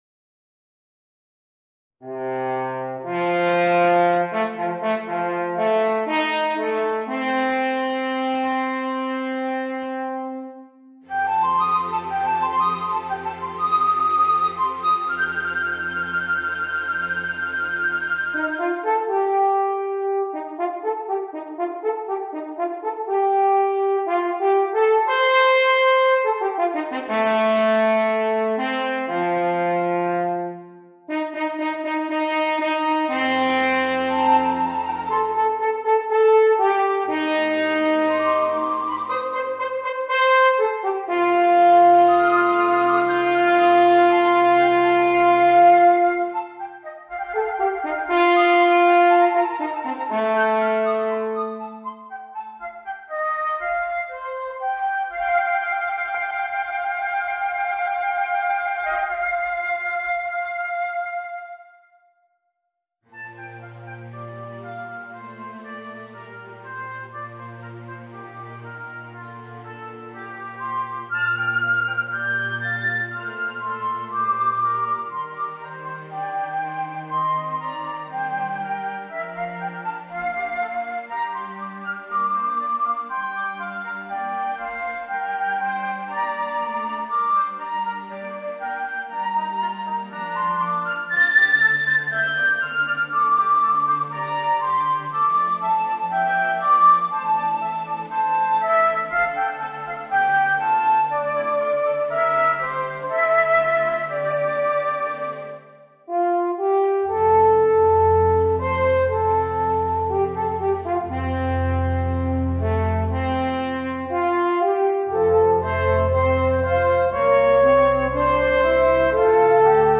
Voicing: Alphorn, Flute and Orchestra